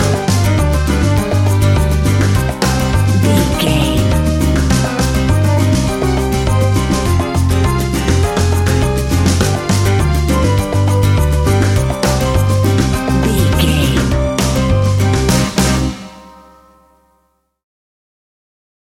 An exotic and colorful piece of Espanic and Latin music.
Ionian/Major
flamenco
romantic
maracas
percussion spanish guitar